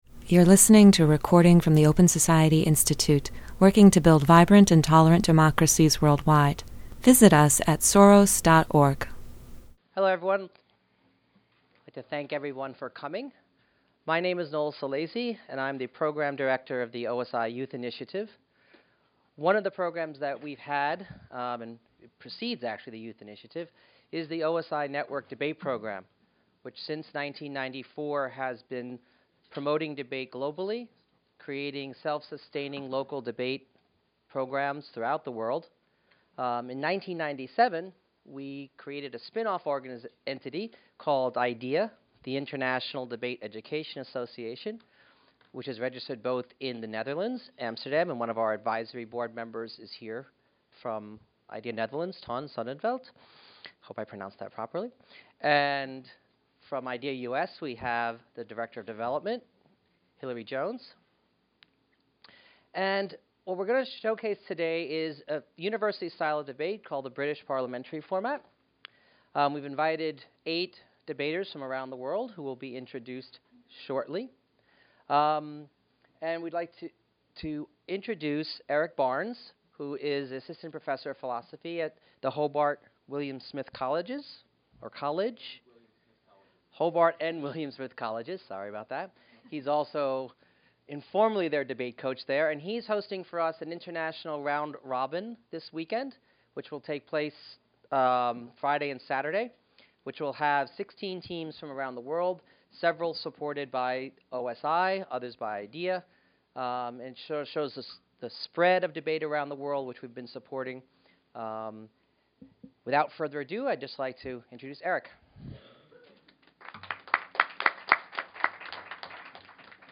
Students Debate Adapting to Vs. Preventing Climate Change
This round-robin invitational debate was organized by the International Debate Education Association and Hobart & William Smith Colleges.